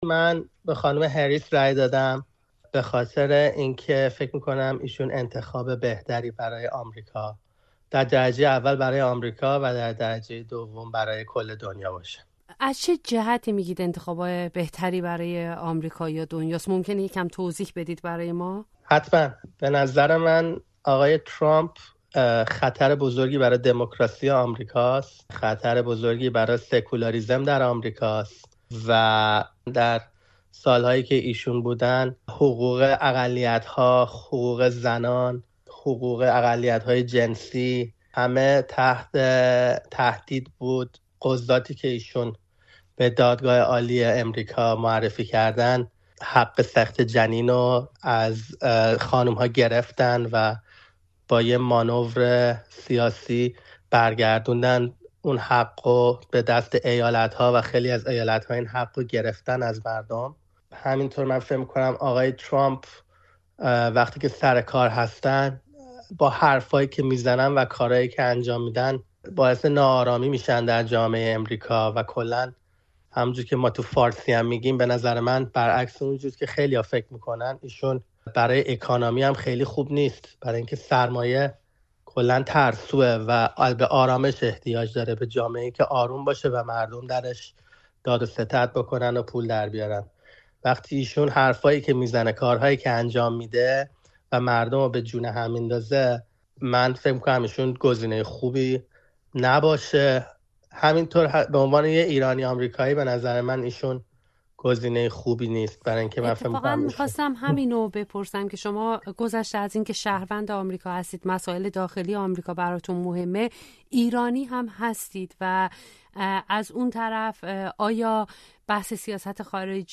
یک آمریکایی ایرانی‌تبارِ هوادار ترامپ از دلایل خود برای رأی دادن به او می‌گوید